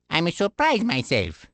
One of Luigi's voice clips from the Awards Ceremony in Mario Kart: Double Dash!!